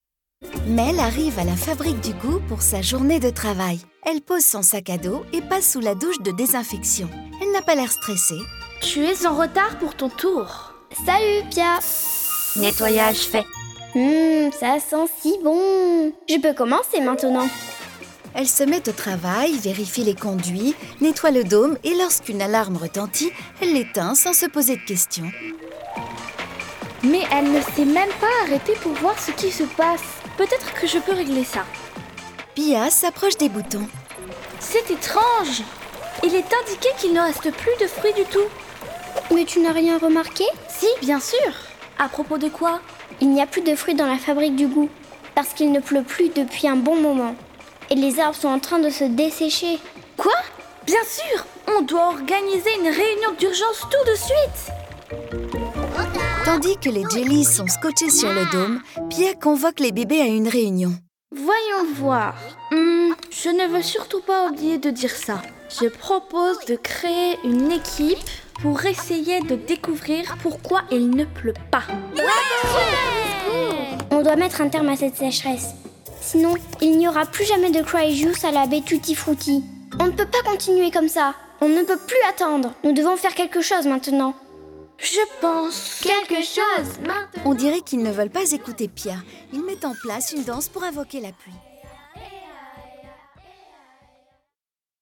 Conte en musique pour Enfants